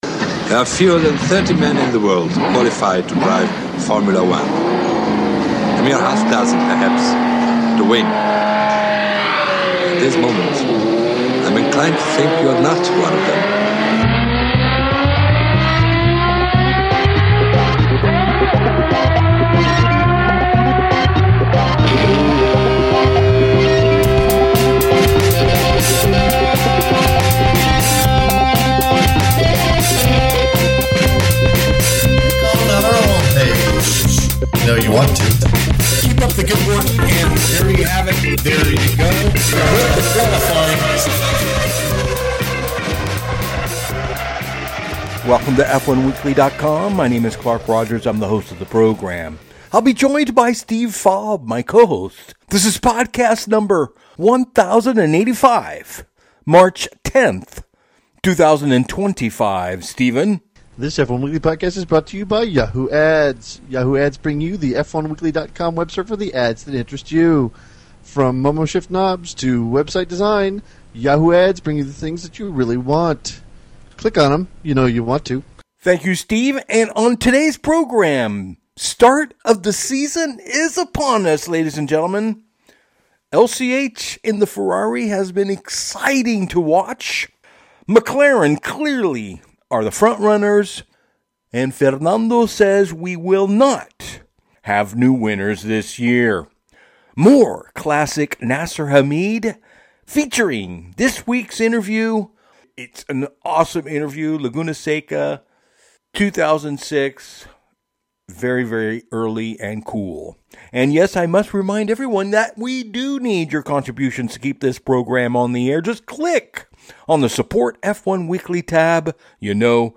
This week we bring you more early Motorsports Mondial an interview with former FIA steward Johnny Herbert!